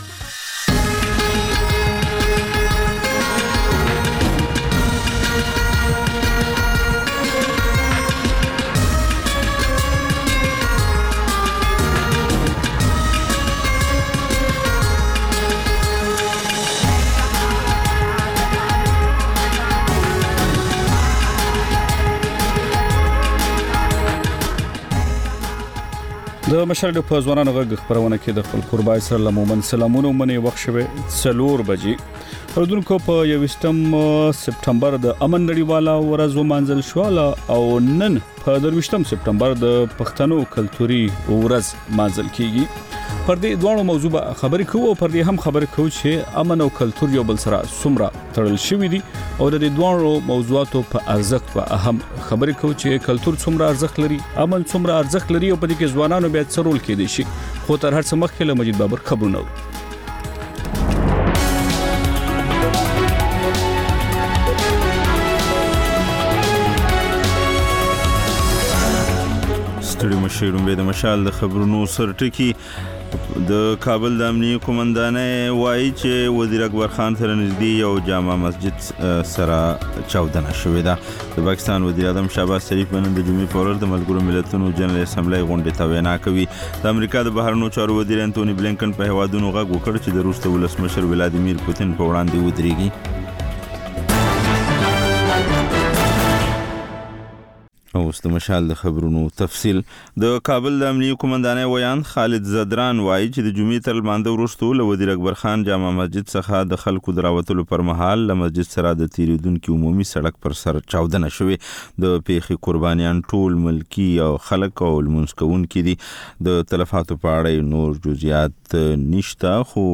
د مشال راډیو مازیګرنۍ خپرونه. د خپرونې پیل له خبرونو کېږي، بیا ورپسې رپورټونه خپرېږي.